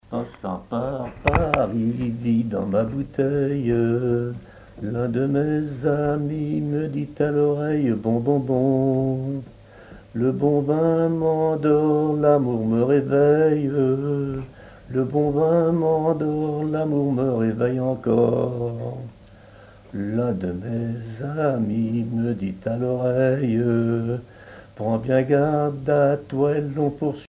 Chansons et commentaires
Pièce musicale inédite